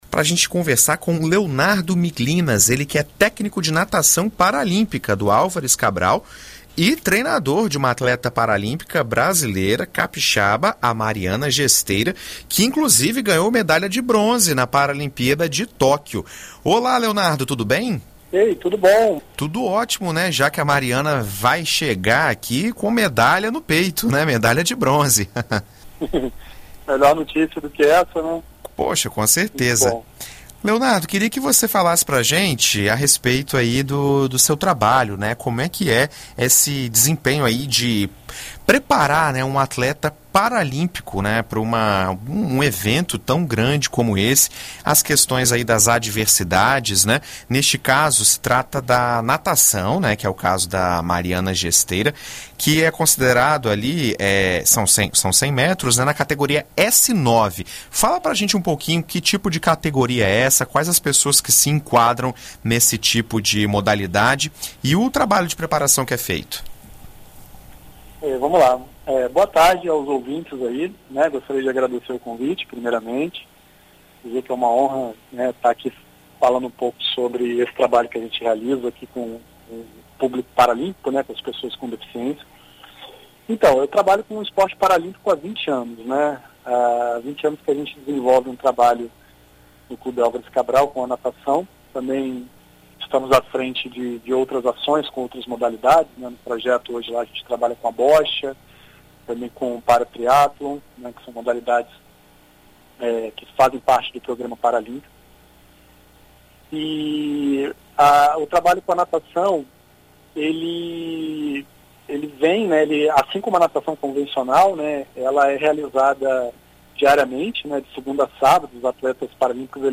Em entrevista à BandNews FM